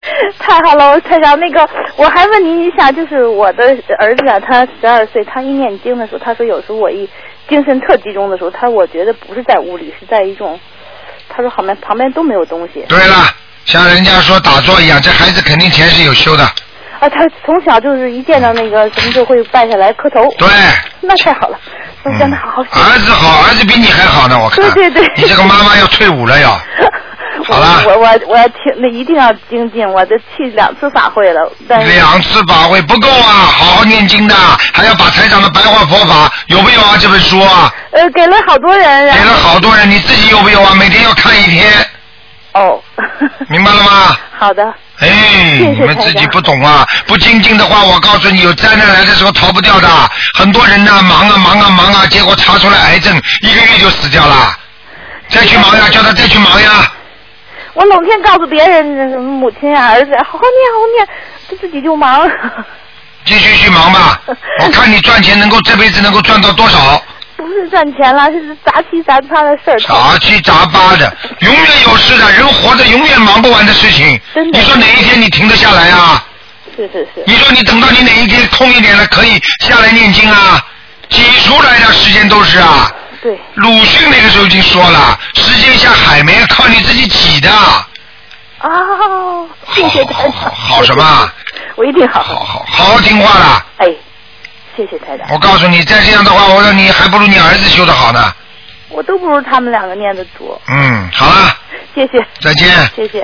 目录：2012年02月_剪辑电台节目录音集锦